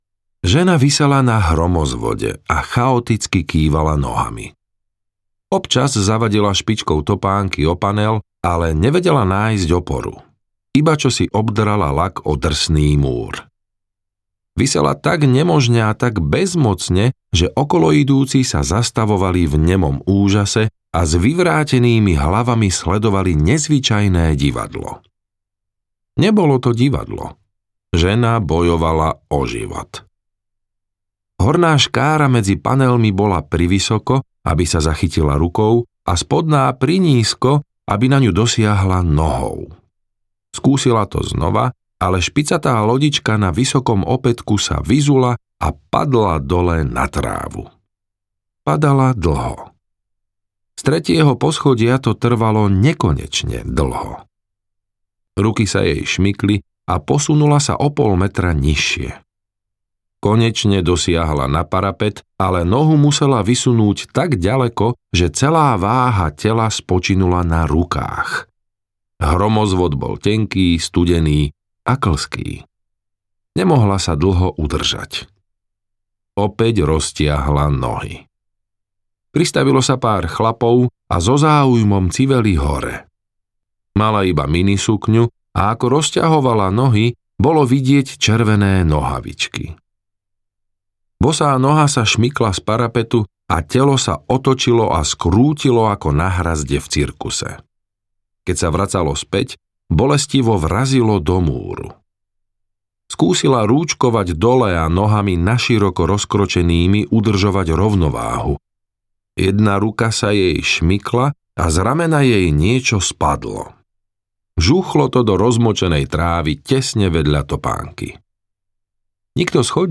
Noc temných klamstiev audiokniha
Ukázka z knihy